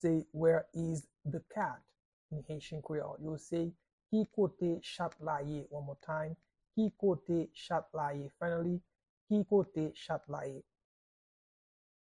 How-to-say-Where-is-the-cat-in-Haitian-Creole-–-Ki-kote-chat-la-ye-pronunciation-by-a-Haitian-native.mp3